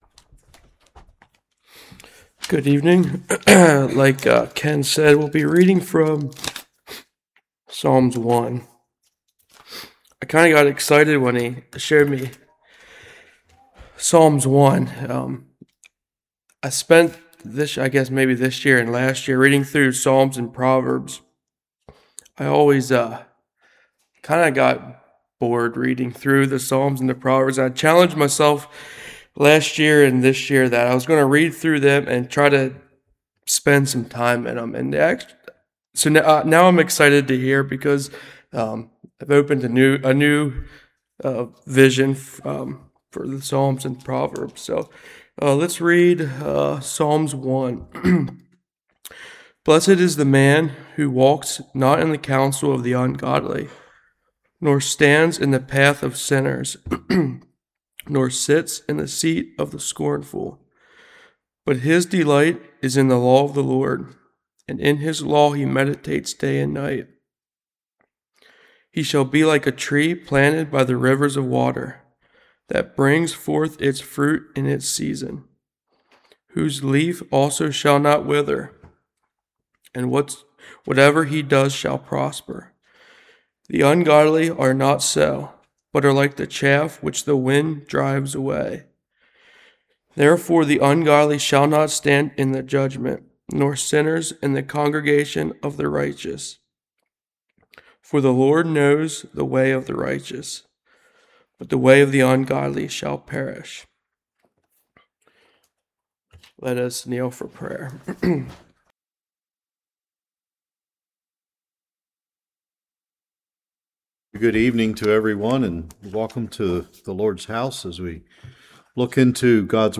Psalm 1 Service Type: Evening What does it mean to be blessed by the means of righteousness?